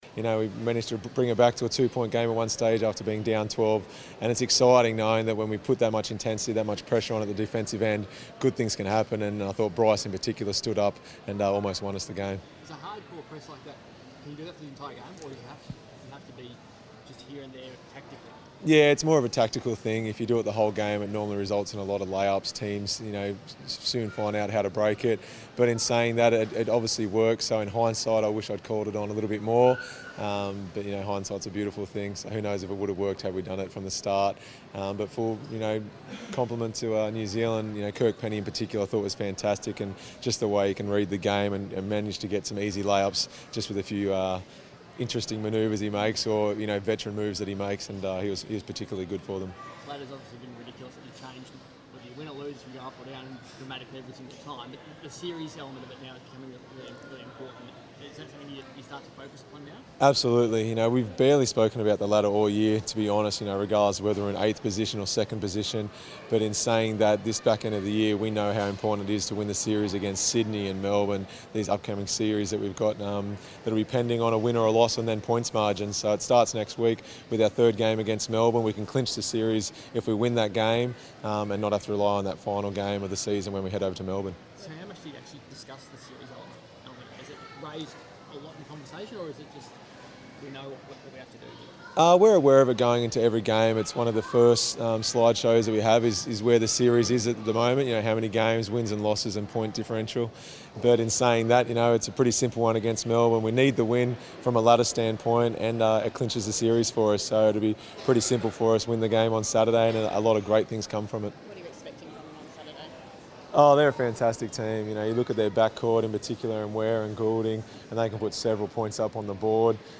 Damian Martin press conference - 23 January 2017